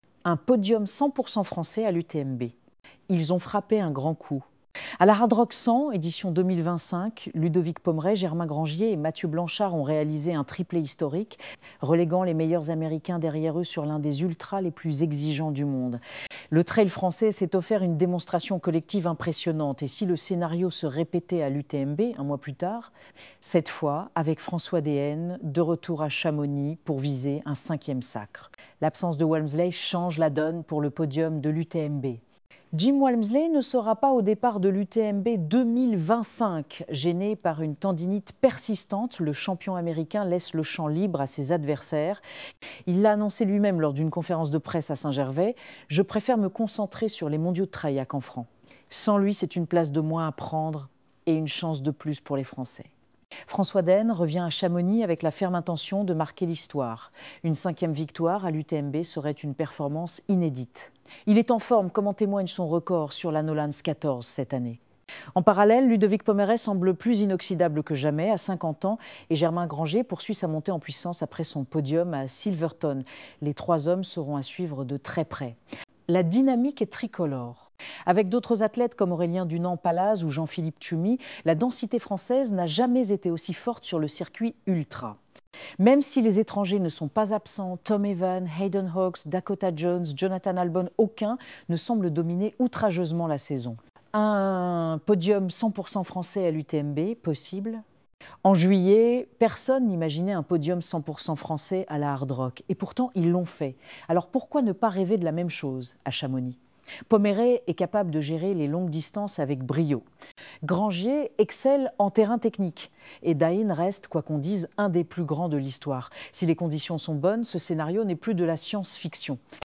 Ecouter cet article sur l’UTMB 2025, les favoris et la probabilité d’un podium 100% français